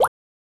Pop (1).wav